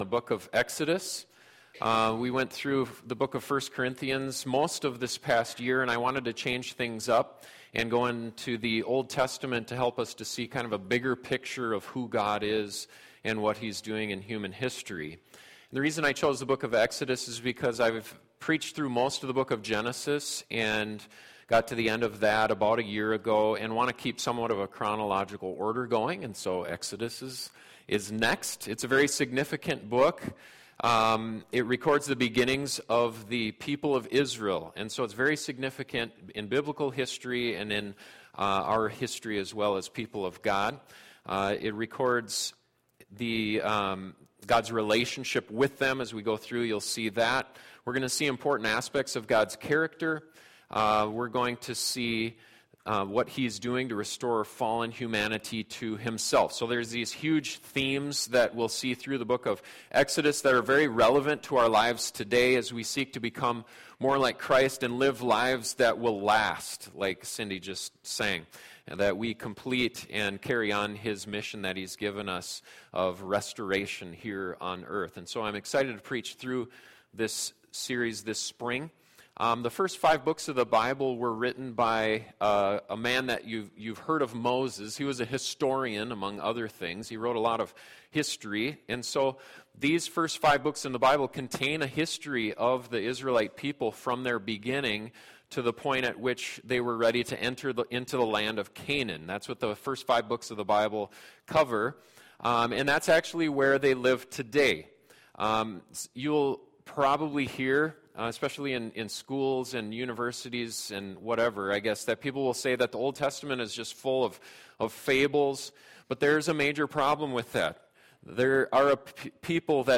This is the introductory message to our new sermon series on the book of Exodus. The things that happened to the people of Israel while they were in Egypt were revealed to Abraham long before they took place. God had a plan that he was carrying out to bless the people of Israel and bring glory to himself.